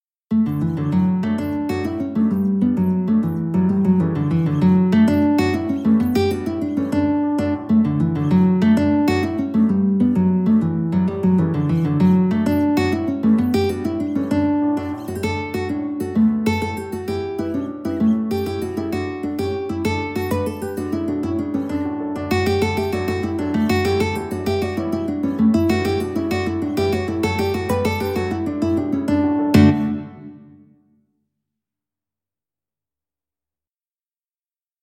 D major (Sounding Pitch) (View more D major Music for Guitar )
6/8 (View more 6/8 Music)
Guitar  (View more Intermediate Guitar Music)
Traditional (View more Traditional Guitar Music)
Jigs for Guitar